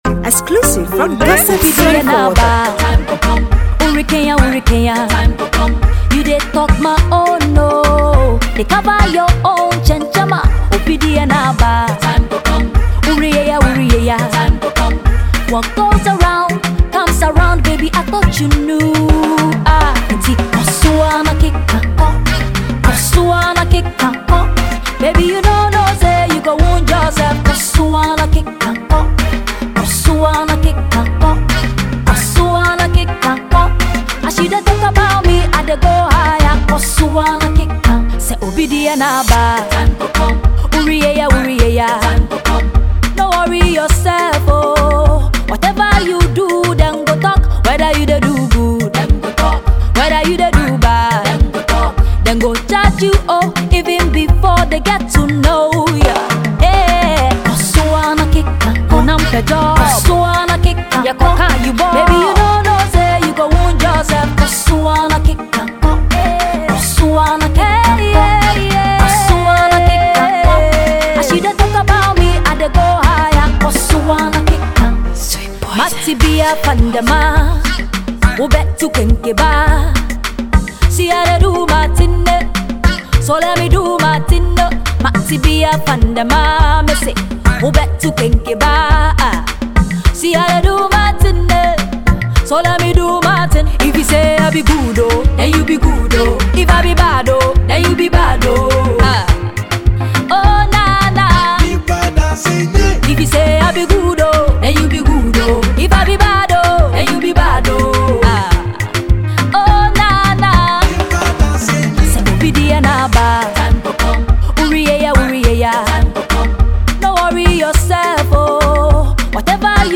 Western based Ghanaian female vocalist